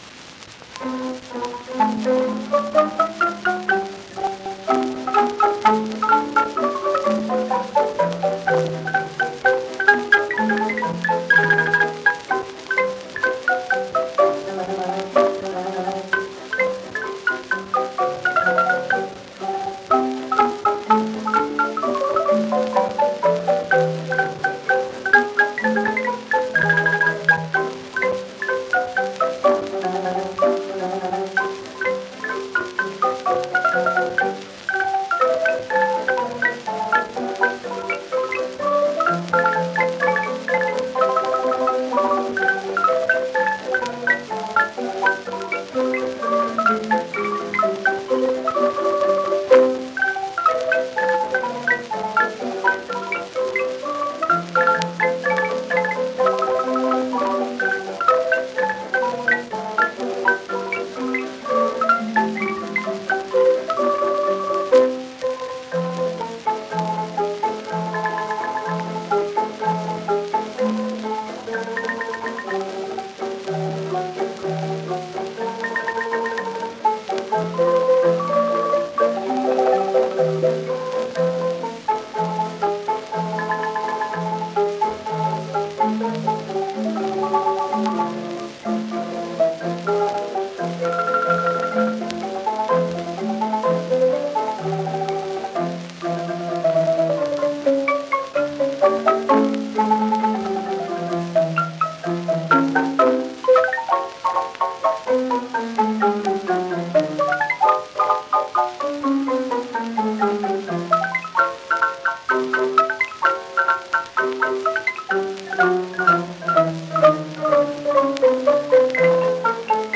AUDIO CREDIT: Imperial Marimba Band, performer.